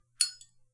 玩家互动 " 打火机打开
描述：打火机